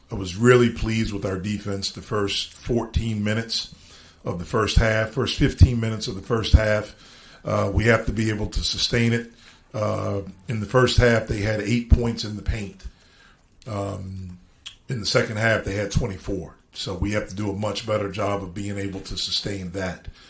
Capel adds they need to do a better job guarding the paint.